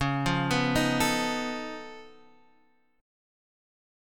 Db+9 chord